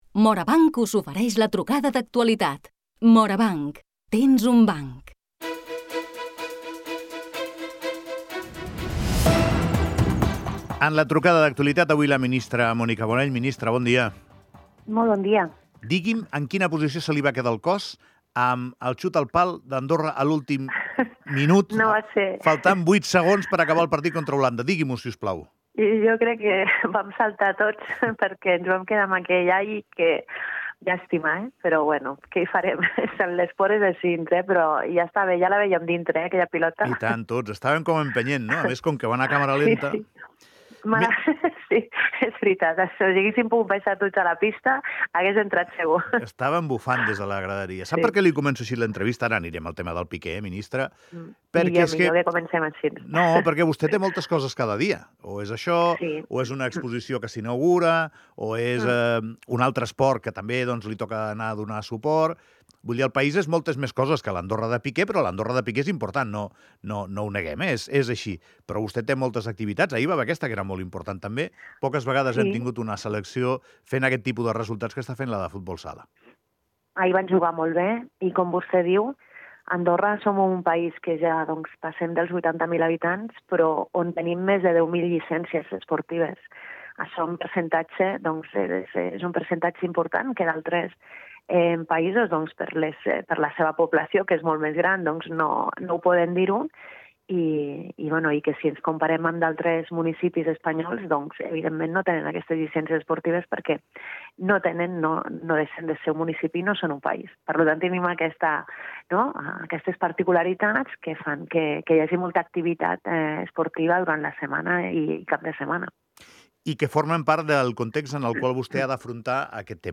Trucada d'actualitat amb la ministra Mònica Bonell
Ho comentem en la trucada d'actualitat amb la ministra de cultura i esports Mònica Bonell.